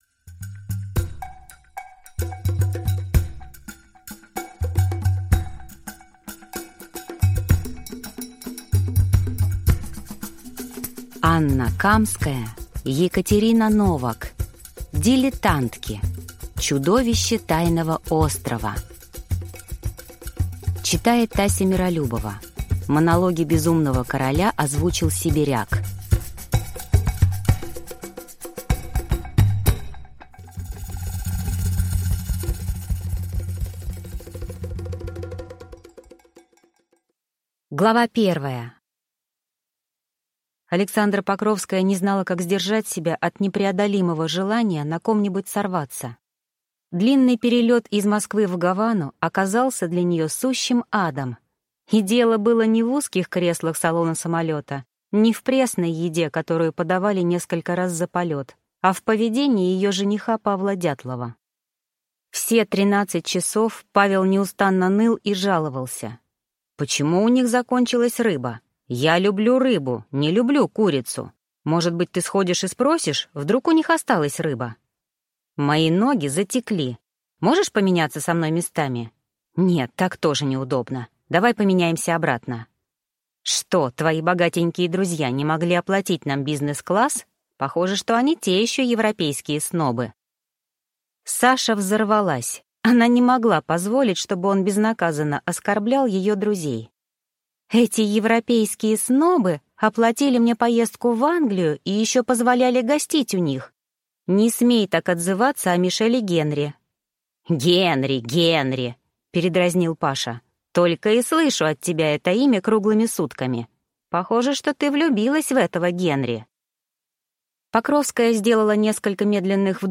Аудиокнига Дилетантки. Чудовище тайного острова | Библиотека аудиокниг